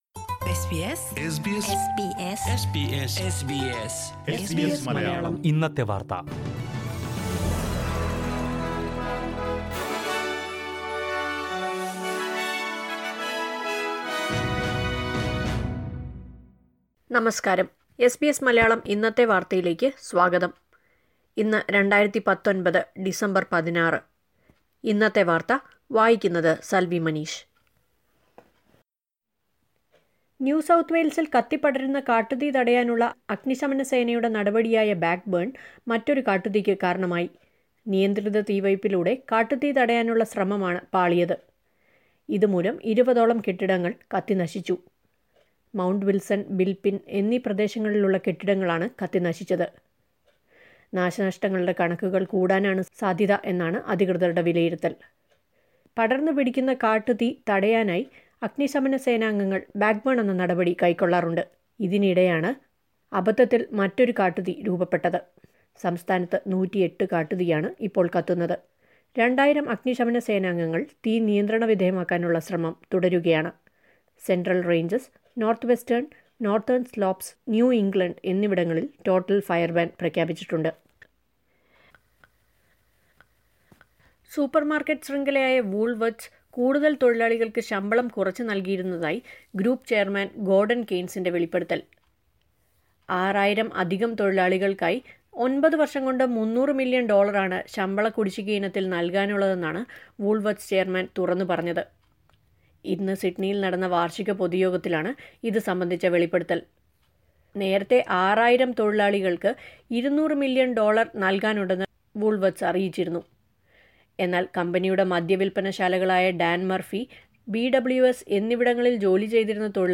2019 ഡിസംബർ 16ലെ ഓസ്ട്രേലിയയിലെ ഏറ്റവും പ്രധാന വാർത്തകൾ കേൾക്കാം.
news_dec16_2.mp3